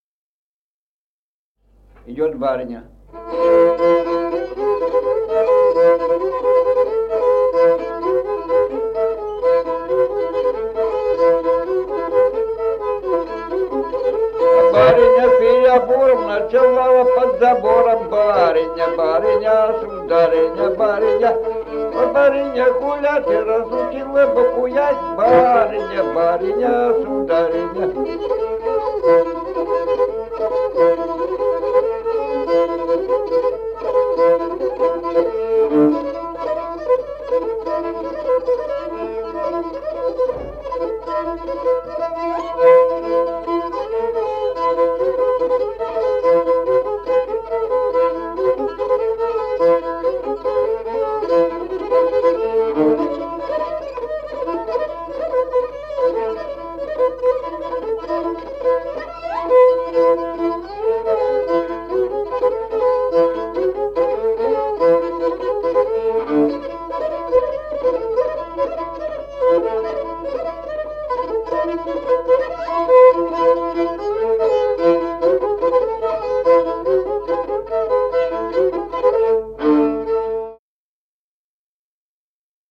Музыкальный фольклор села Мишковка «Барыня», репертуар скрипача.